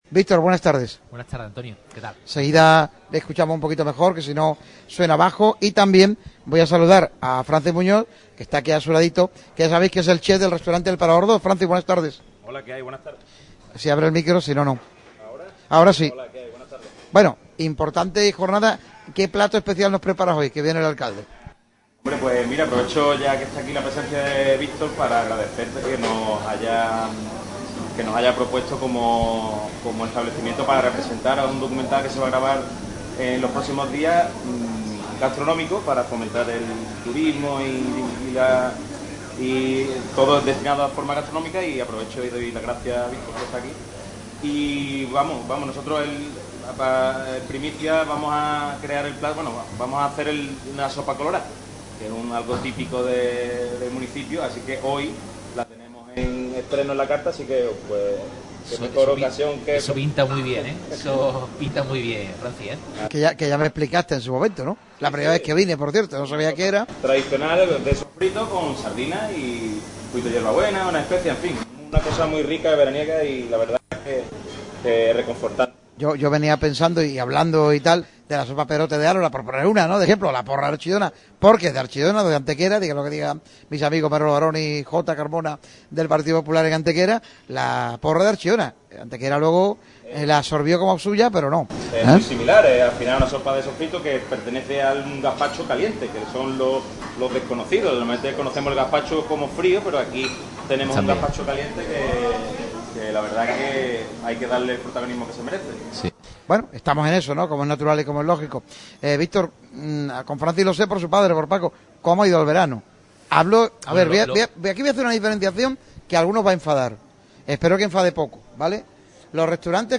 El alcalde de Benalmádena, Víctor Navas, atendió a los micrófonos de Radio Marca Málaga en el programa emitido desde el restaurante 'El Parador II'.